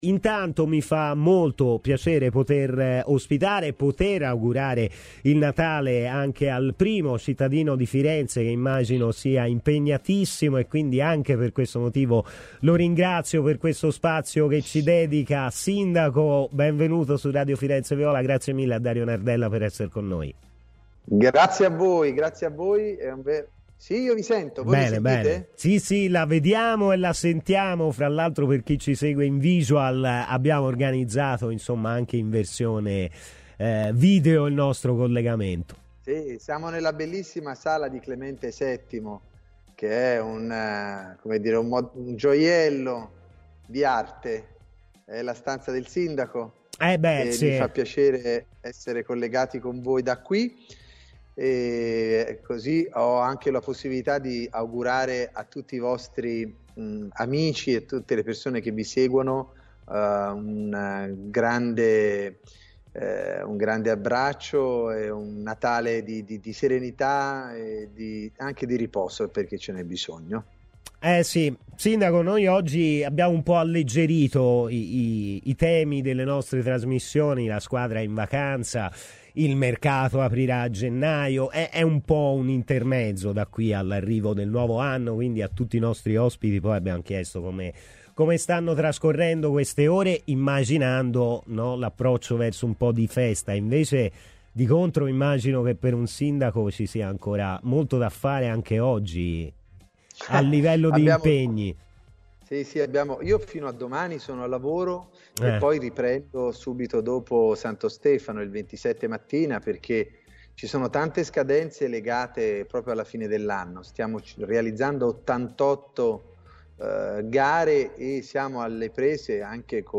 Il sindaco di Firenze Dario Nardella ha parlato in esclusiva su Radio Firenzeviola per augurare un buon natale a tutti i tifosi della Fiorentina e parlare delle situazioni legate al Franchi e al Viola Park: "Vi ringrazio per avermi chiamato, sono molto contento di essere collegato con voi e colgo l'occasione di augurare a tutti i vostri amici che vi seguono un grande abbraccio e un buon natale. Io sono a lavoro fino a domani e riprenderò i lavori il 27 mattina, ci sono tante scadenze legate alla fine dell'anno, abbiamo avuto problemi dell'ultima ora come lo sciopero dei sindacati al teatro del Maggio, il mio lavoro non si ferma mai".